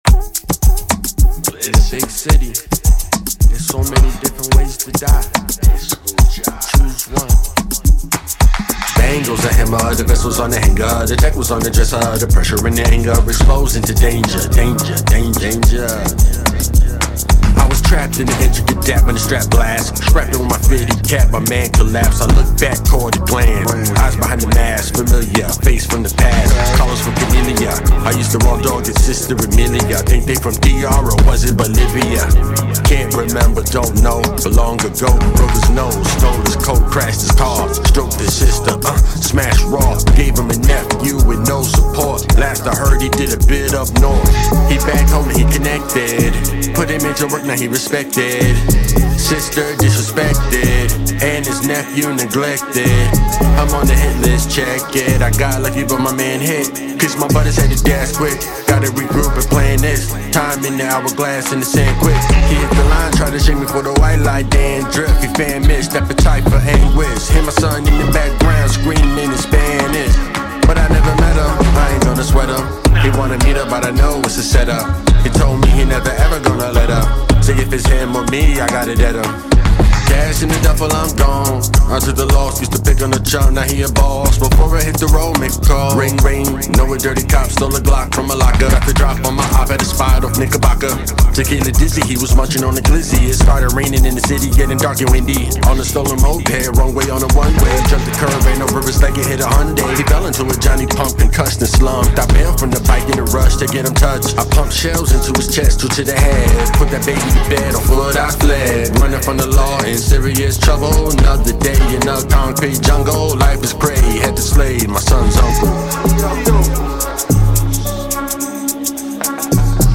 well it takes time but the song is fast...